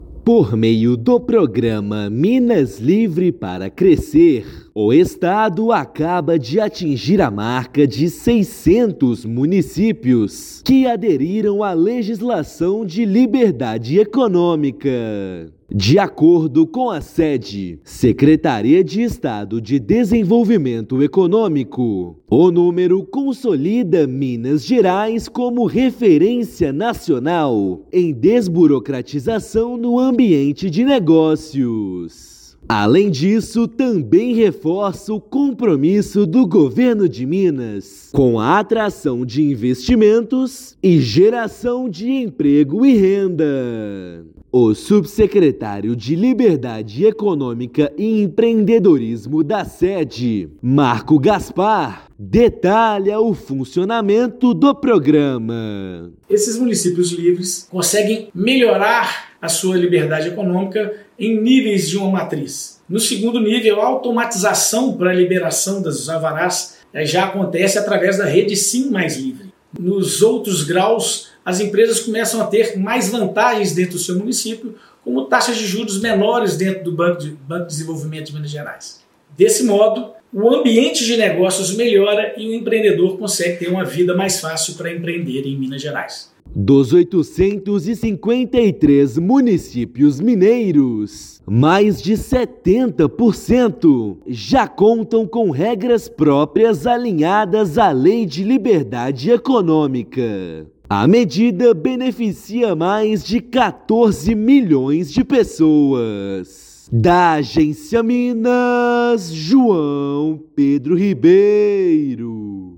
Com o maior número de municípios no Brasil que possuem regulamentação própria da legislação, estado tem alcançado recordes de empresas abertas, investimentos privados atraídos e geração de empregos. Ouça matéria de rádio.